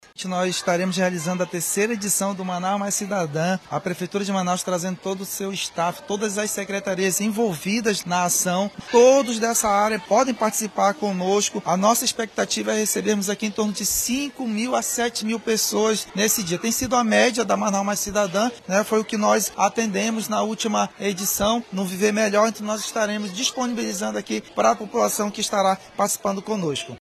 SONORA01_MANAUS-MAIS-CIDADA.mp3